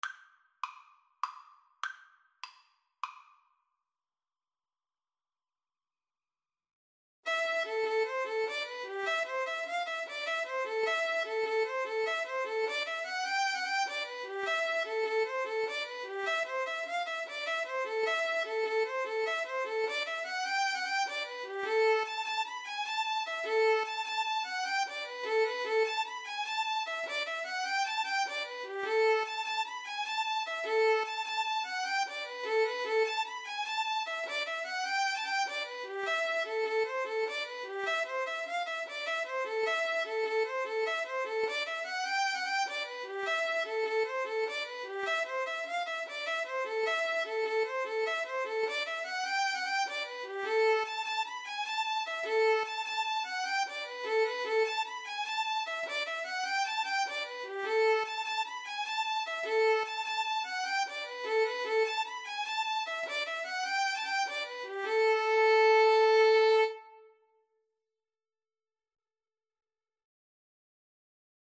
Play (or use space bar on your keyboard) Pause Music Playalong - Player 1 Accompaniment reset tempo print settings full screen
A minor (Sounding Pitch) (View more A minor Music for Violin Duet )
9/8 (View more 9/8 Music)
Traditional (View more Traditional Violin Duet Music)